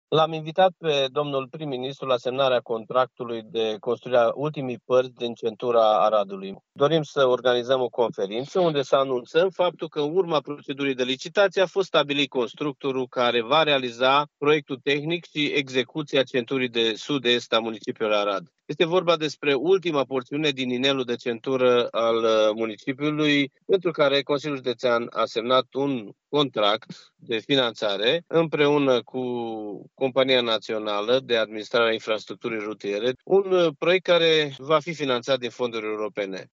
Semnarea contractului într-un cadru oficial va avea loc vinerea viitoare, la sediul Consiliul Judeţean Arad, spune președintele instituției, Iustin Cionca.